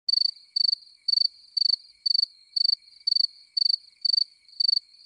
Grilos